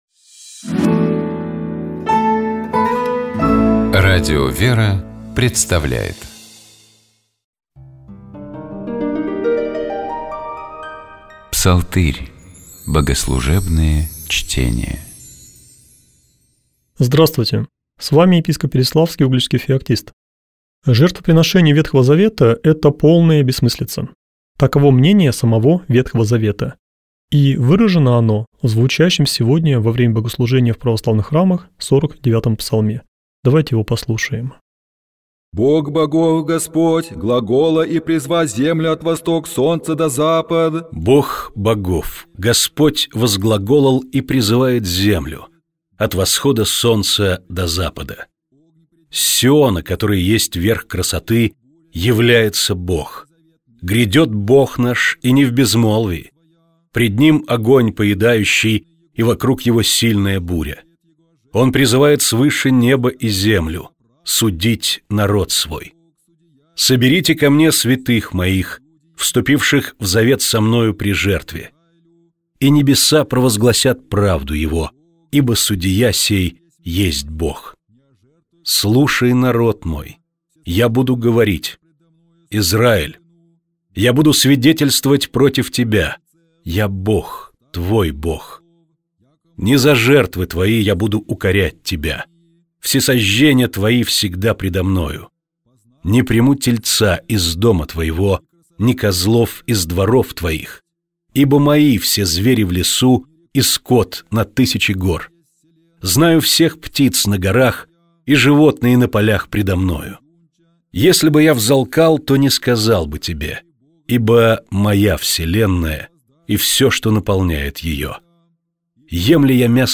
Псалом 49. Богослужебные чтения